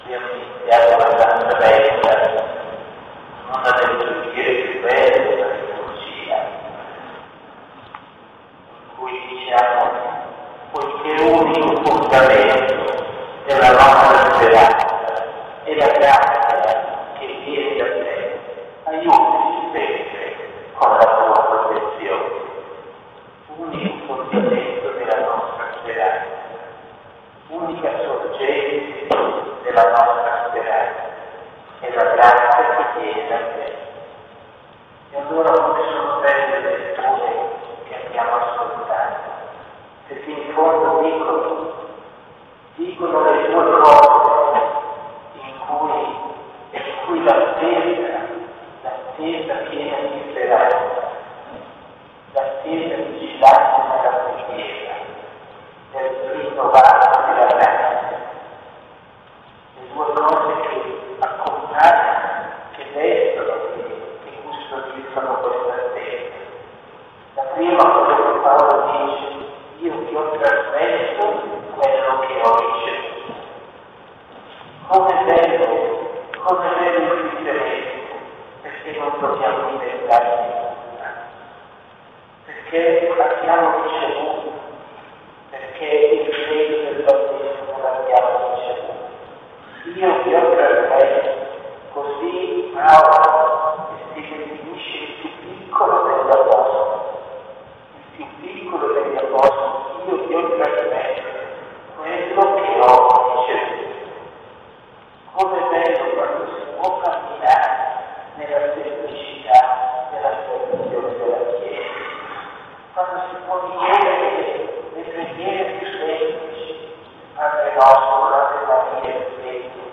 OMELIA V Domenica di Avvento